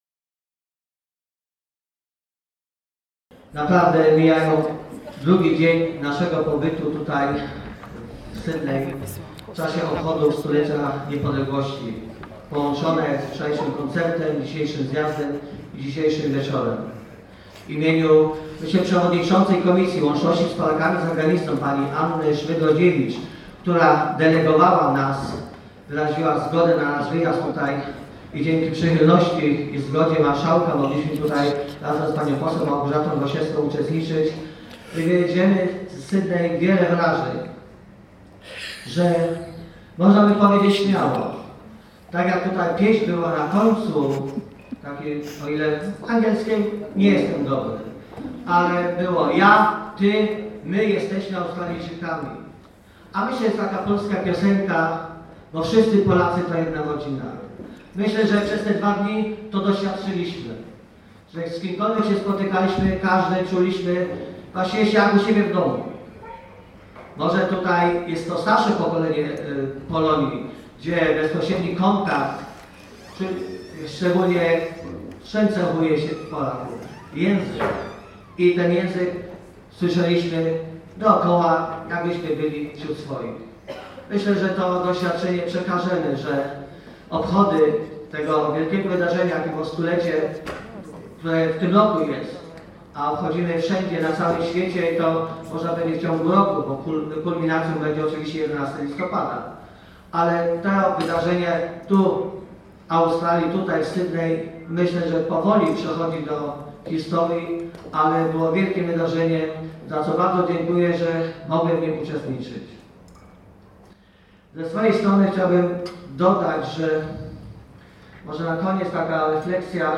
Przesłanie posła Wojciecha Ziemniaka
na koncercie poetycko-muzycznym w Ashfield